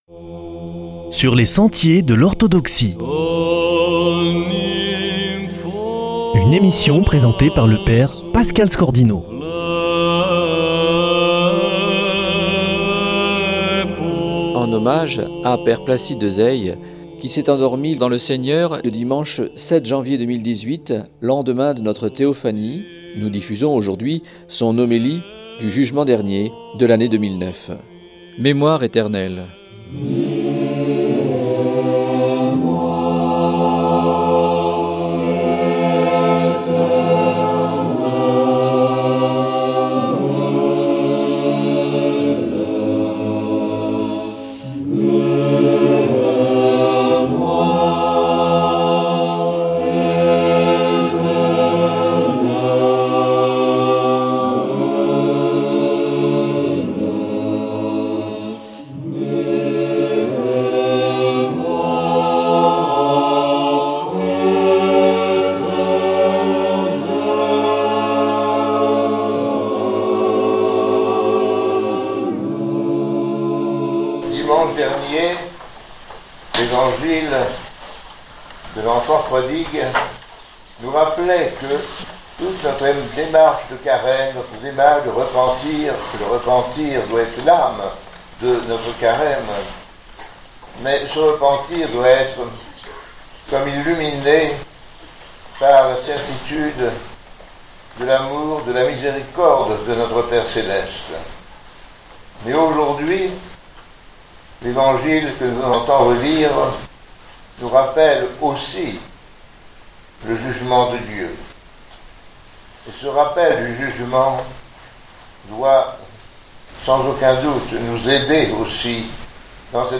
homélie 2009 sur la parabole du Jugement Dernier new